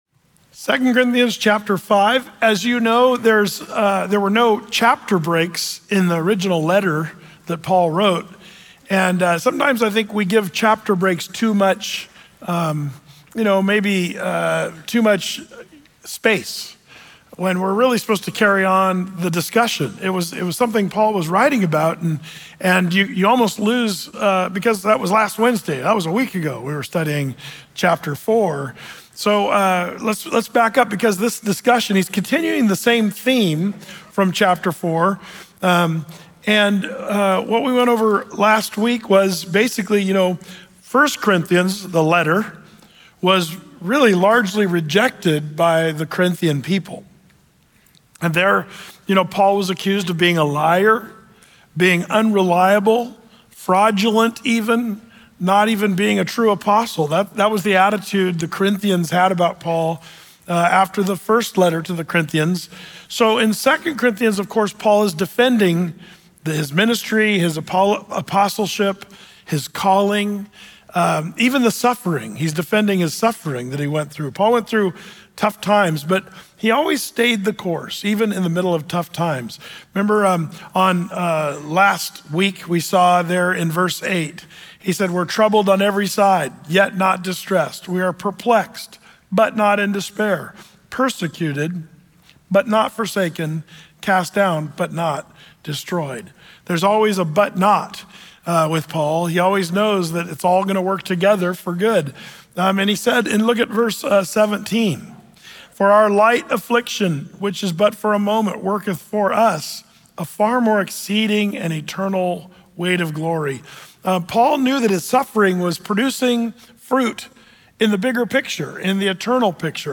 Through-the-Bible teaching